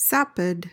PRONUNCIATION: (SAP-id) MEANING: adjective: 1.
sapid.mp3